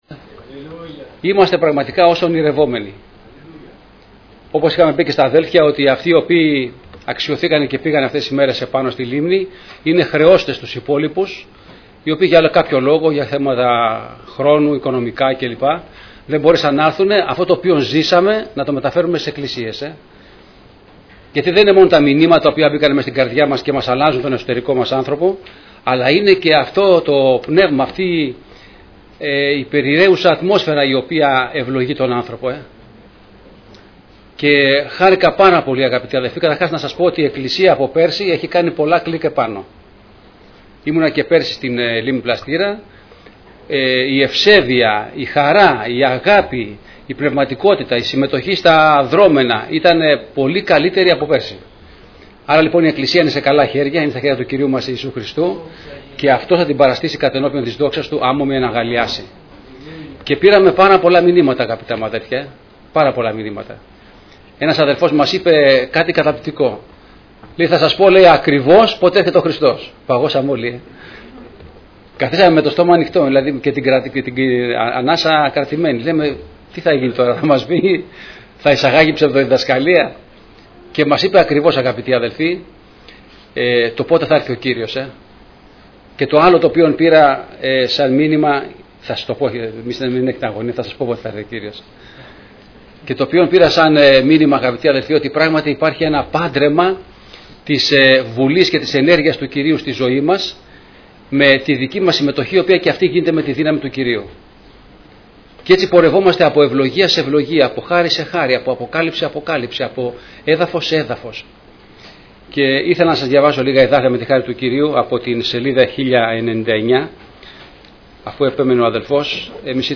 Ομιλητής: Διάφοροι Ομιλητές
Κηρύγματα